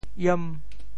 潮州 iem5 文 对应普通话: yán ①无机化合物，一种有咸味的无色或白色结晶体，成分是氯化钠，用来制造染料、玻璃、肥皂等，亦是重要的调味剂和防腐剂（有“海盐”、“池盐”、“井盐”、“岩盐”等种类）：～巴 | ～卤 | ～分（fèn ） | ～田。